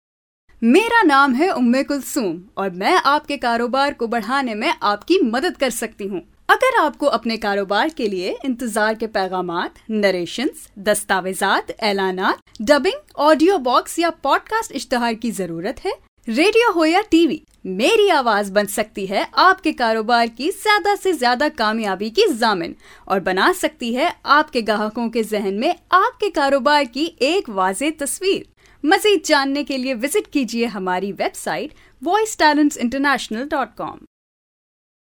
Urduca Seslendirme
Kadın Ses